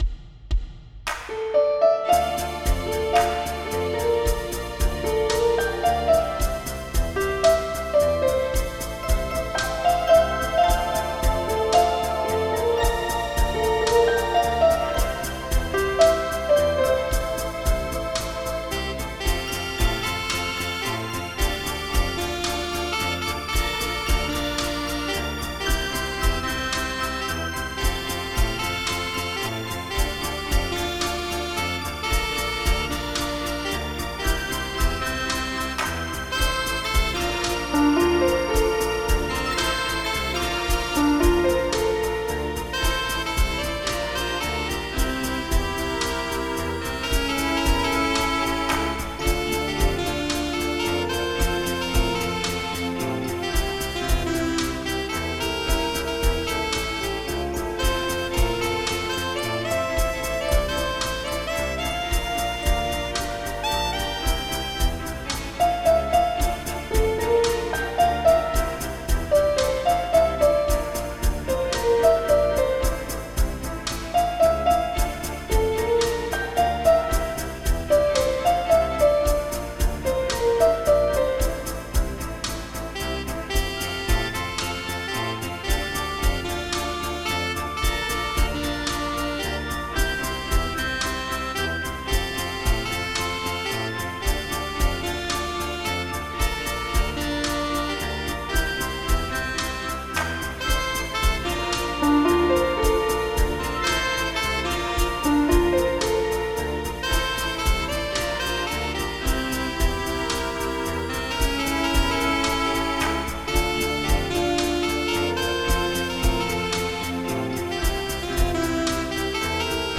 Pop
MIDI Music File
Type General MIDI